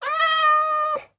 Minecraft Version Minecraft Version 1.21.5 Latest Release | Latest Snapshot 1.21.5 / assets / minecraft / sounds / mob / cat / stray / idle3.ogg Compare With Compare With Latest Release | Latest Snapshot